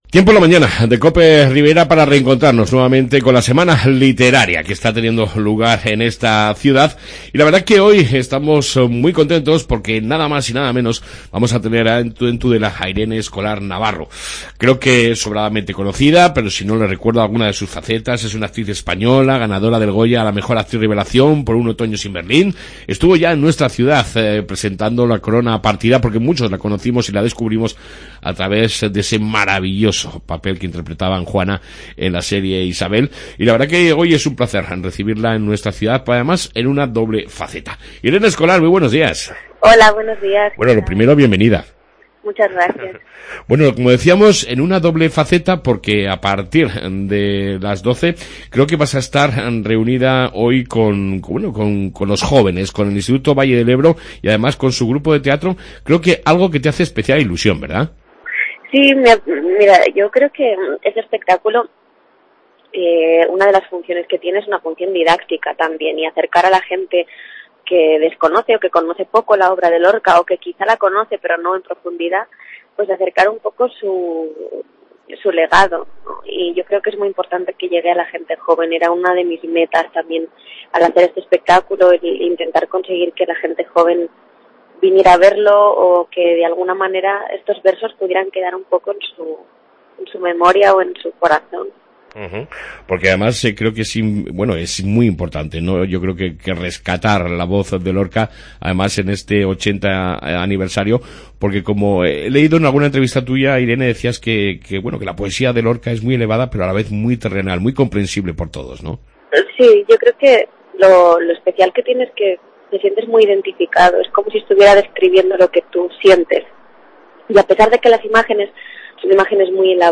AUDIO: Hoy 27/01 ha sido un verdadero placer charlar ampliamente con la actriz Irene Escolar sobre Lorca, la poesia, el amor....la radio te ofrece...